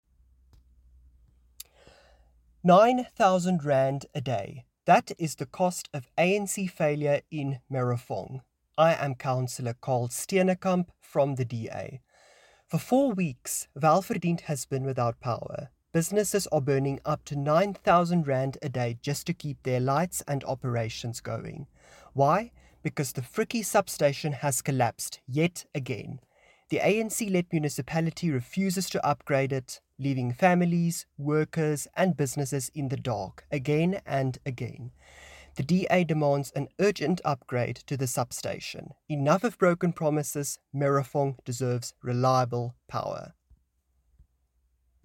Note to Editors: Please find an English soundbite by Cllr Carl Steenekamp